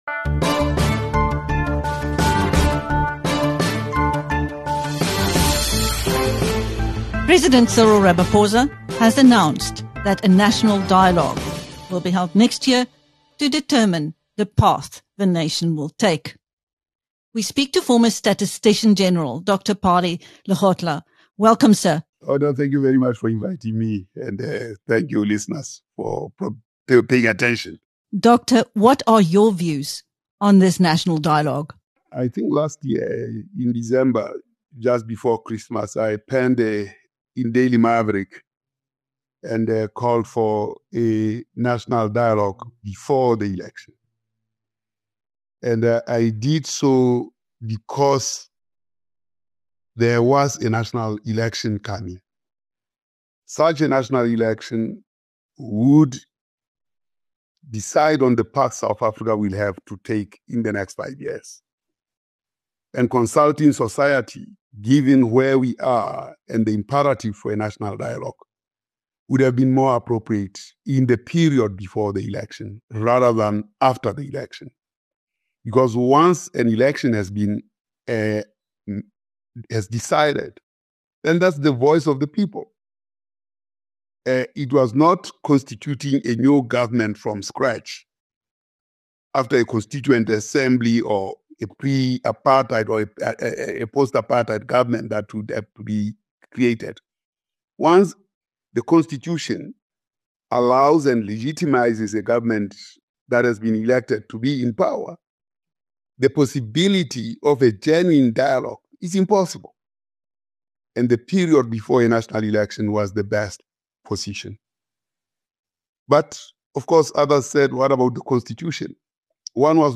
Following the announcement by President Cyril Ramaphosa that a National Dialogue will be held next year to determine the path the nation will take, BizNews spoke to former Statistician-General Dr. Pali Lehohla.